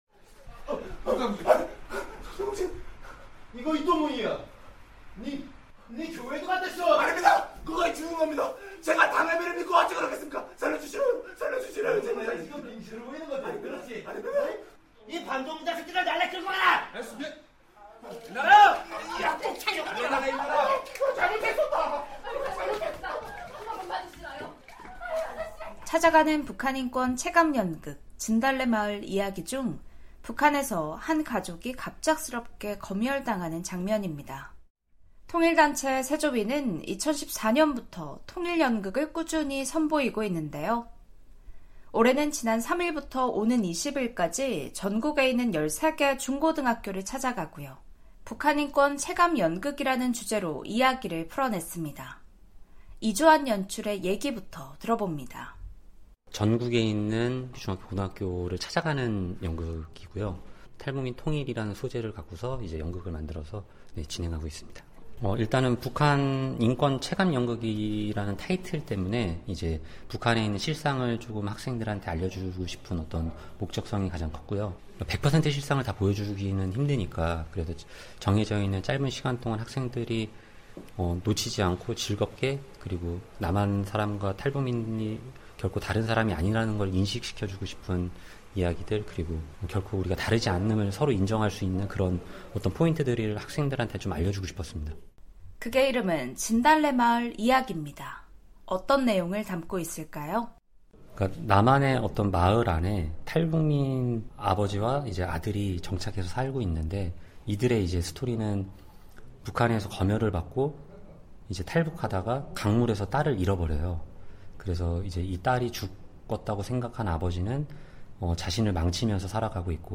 한국 청소년에게 북한 인권의 현실을 알리고 탈북민의 정착 문제에 대한 이해를 높이기 위해 마련된 건데요. 탈북민들의 다양한 삶의 이야기를 전해드리는 '탈북민의 세상 보기', 오늘은 '새롭고하나된조국을위한모임, 새조위'가 마련한 찾아가는 북한인권 체감연극, '진달래 마을 이야기' 현장으로 안내해 드립니다.